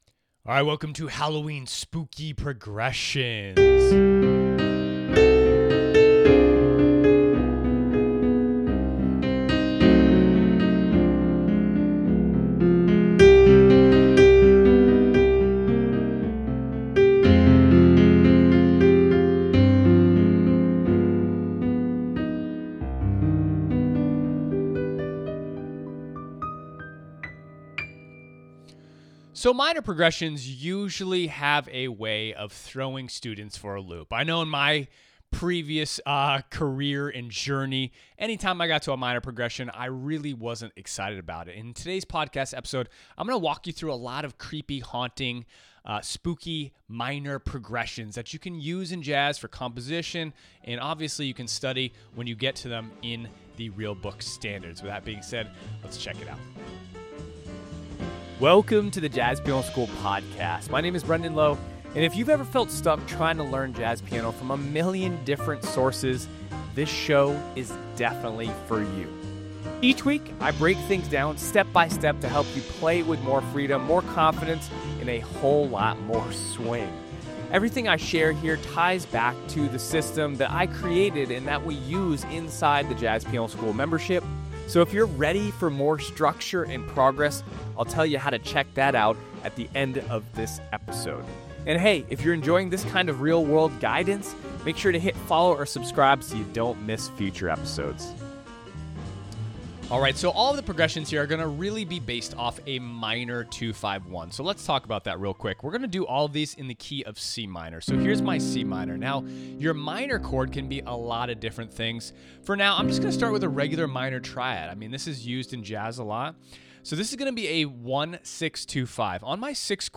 In this episode, I’ll show you 5 haunting minor progressions that completely transform the mood of your playing — from eerie to elegant, from ghostly to film-score beautiful.